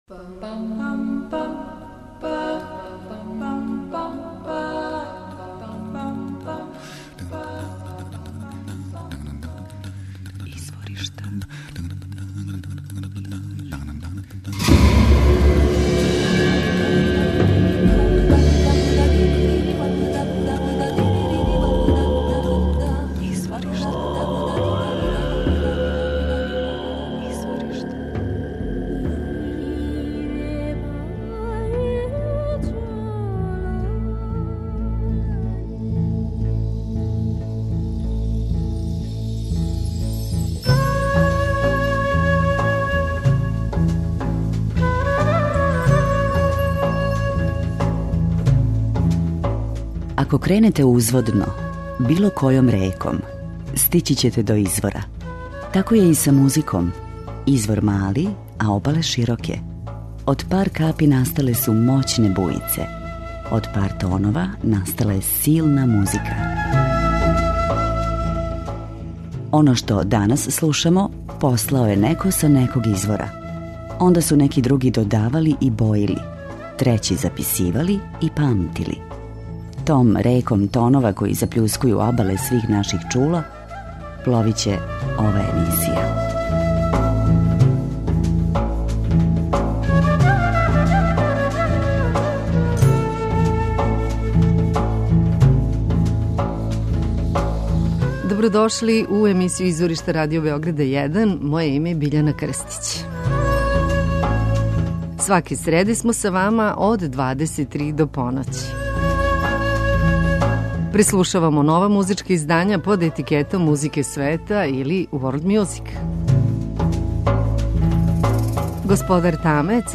Прошле године на концерту, у Лондону, појавио се као латинска верзија (Charlie Chaplin) Чарли Чаплина и (Tom Waits) Том Вејтса и завршио наступ бацајући се на земљу, један од великих ексцентрика и представник оригиналне аргентинске музичке сцене, некадашњи експериментални рокер, са промуклим гласом и са изразитим позоришним стилом, мултиинструменталиста Daniel Melingo.
Текстови песама говоре о сиромашном животу у Буенос Аиресу, али они су постављњени насрпам разумних, шармантних наслова песама. Овај албум је микс танга са осталим аргентинским стиловима.
преузми : 28.66 MB Изворишта Autor: Музичка редакција Првог програма Радио Београда Музика удаљених крајева планете, модерна извођења традиционалних мелодија и песама, културна баштина најмузикалнијих народа света, врели ритмови...